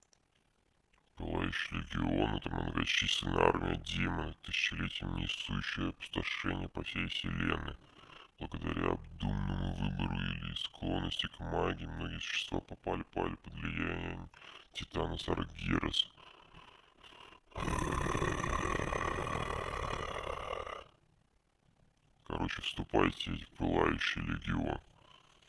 Варианты изменения голоса вы можете видеть на скриншоте.
Записал я звук в WAV для быстроты стандартными средствами Windows.
Malganis
burninglegion.wav